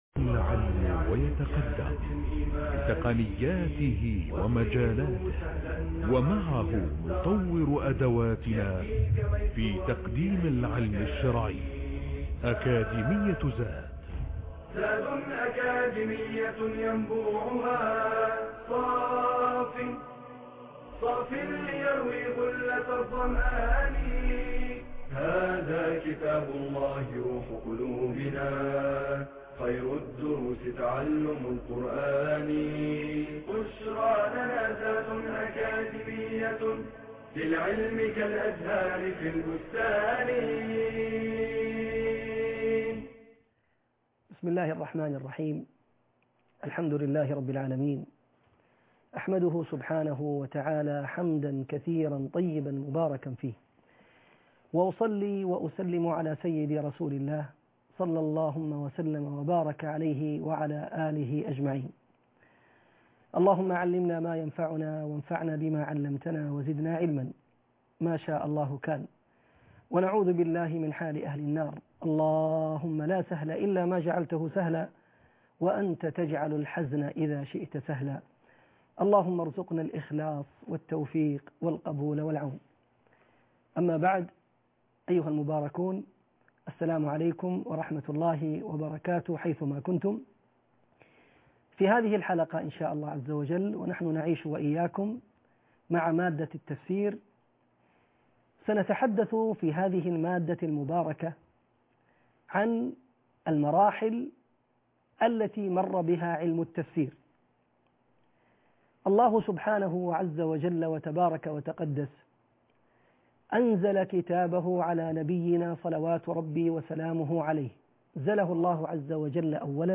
المحاضرة الثانيه _ الدورة الثانيه -مراحل تفسير القران